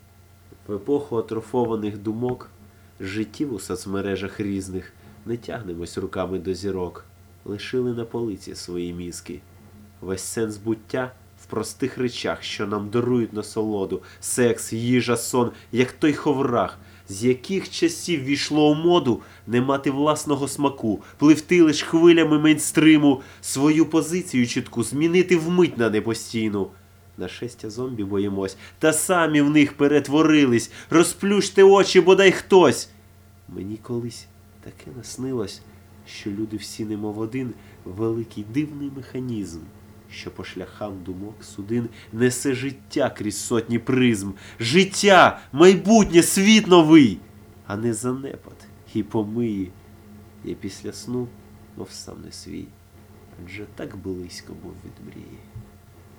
у вас голос хороший
скорее хороший диктофон biggrin